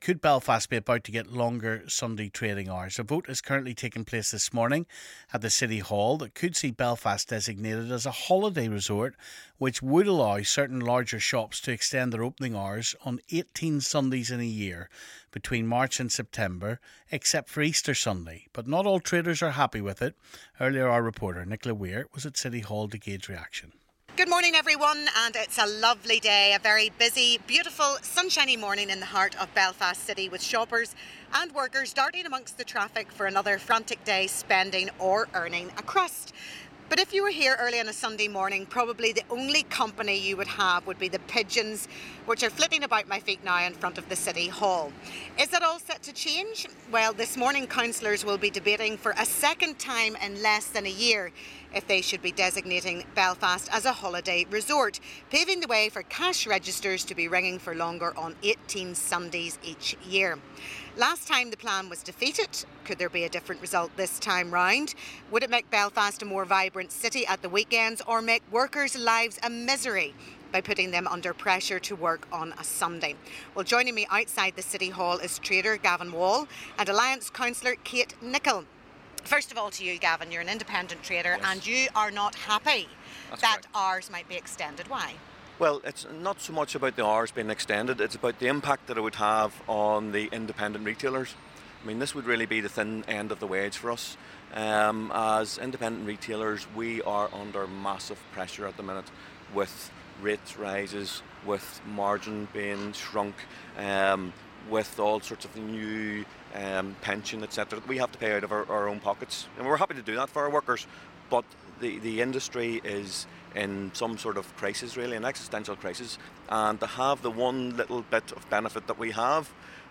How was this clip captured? went to City Hall to gauge reaction